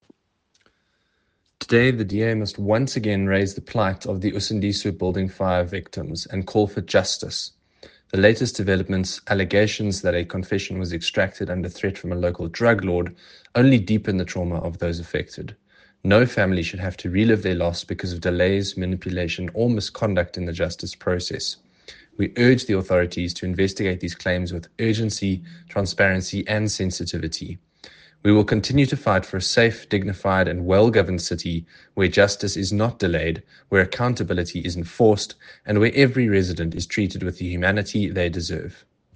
Note to Editors: Please find an English soundbite by Andrew de Blocq MP